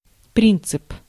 Ääntäminen
Tuntematon aksentti: IPA: /pʁɛ̃.sip/